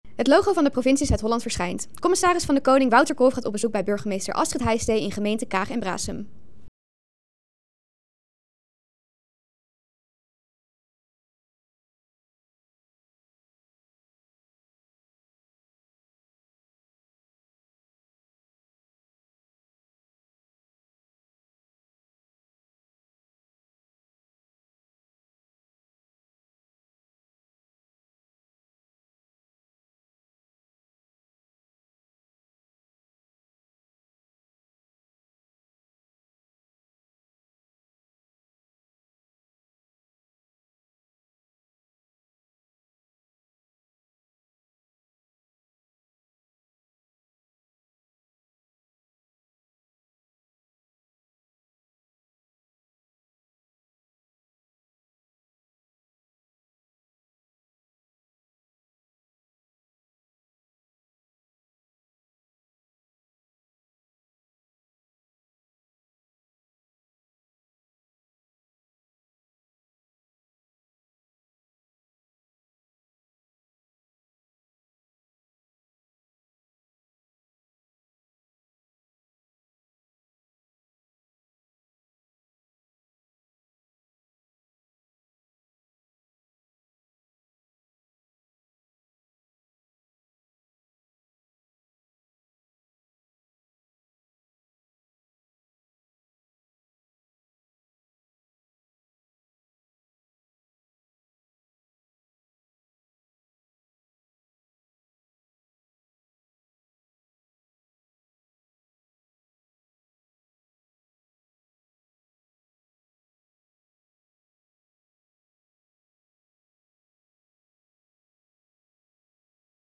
In gesprek met burgemeester Kaag en Braassem
De commissaris van de Koning bezoekt de komende tijd alle 50 gemeenten van Zuid-Holland. In deze video gaat hij in gesprek met de burgemeester van Kaag en Braassem.